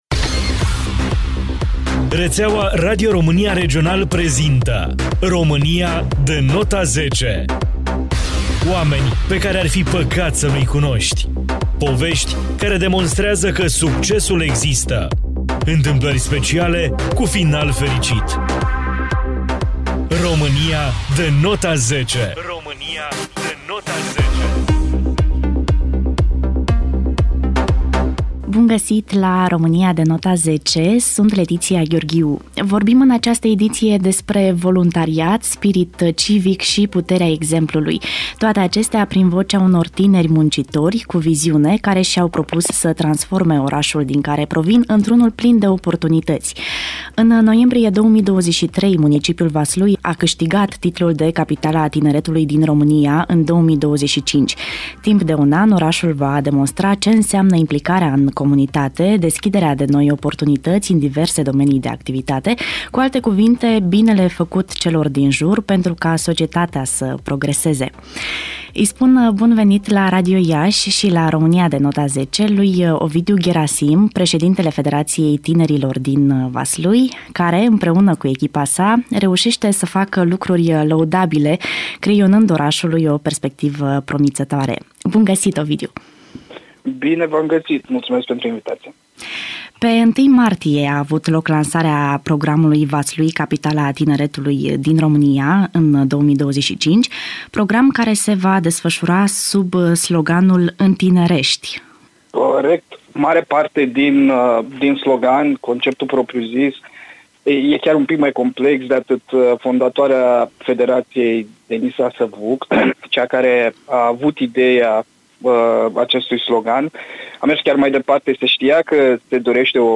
În ediția din această săptămână a emisiunii „România de nota 10” vorbim despre voluntariat, spirit civic și puterea exemplului. Toate acestea, prin vocea unor tineri muncitori, cu viziune, care și-au propus să transforme orașul din care provin într-unul ofertant.